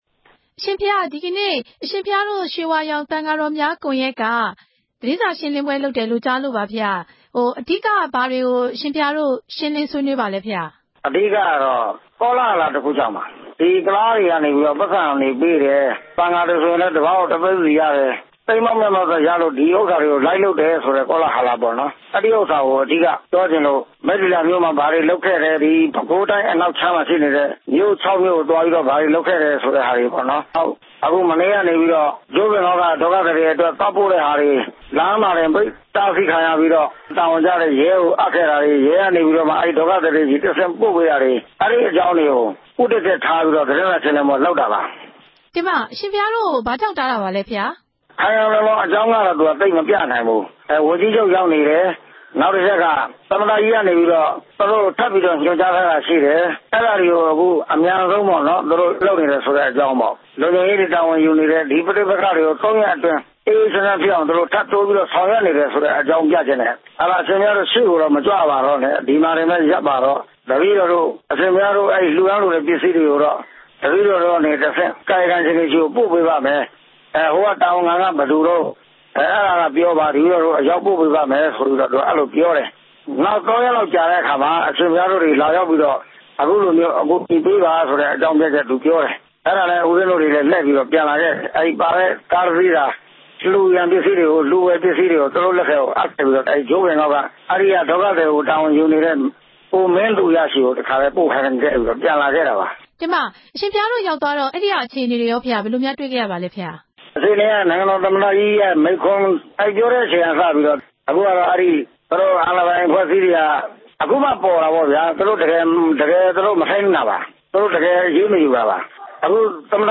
RFA က ဆက်သွယ်မေးမြန်းခဲ့ပါတယ်။